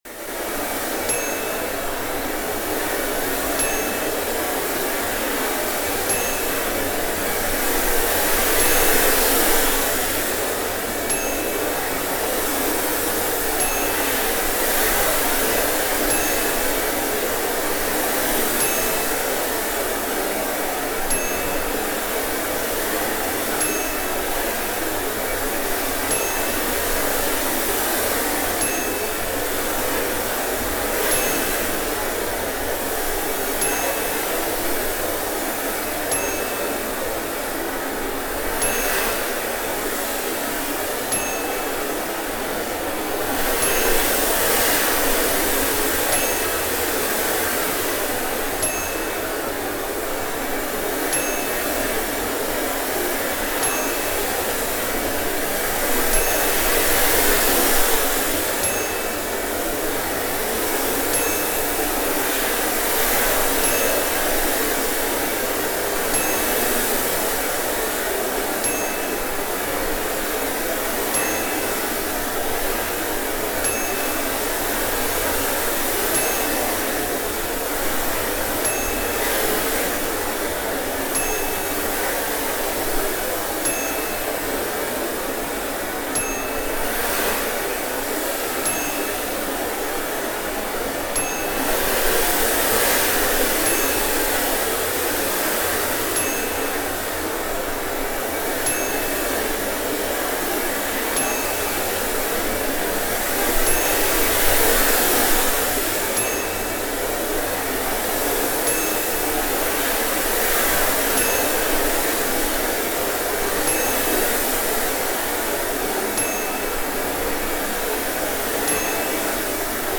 Halloween Wind Blowing Sound Effect
Scary wind blowing through trees. Very clean recording with no unwanted noise.
ScaryHalloweenWind.mp3